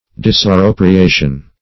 Disappropriation \Dis`ap*pro`pri*a"tion\, n. The act of disappropriating.